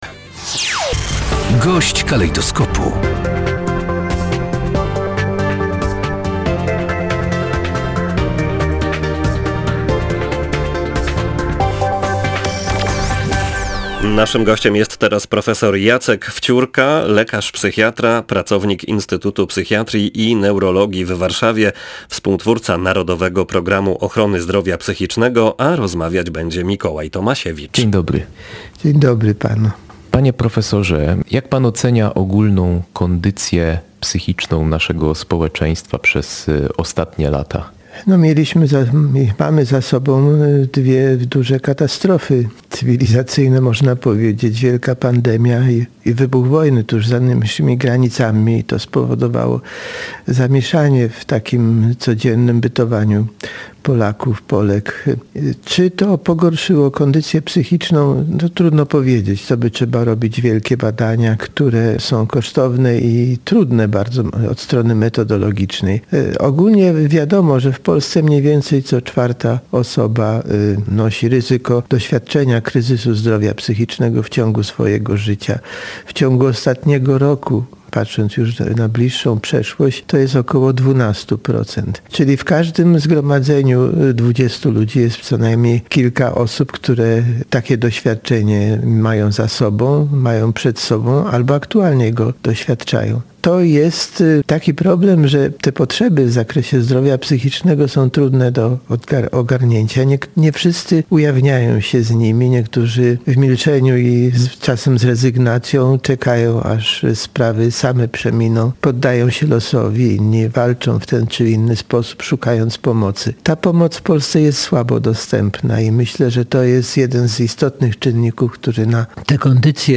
Gość dnia • Jedna czwarta Polaków narażona jest w ciągu swojego życia na doświadczenie kryzysu zdrowia psychicznego, najczęściej zaburzeń lękowych i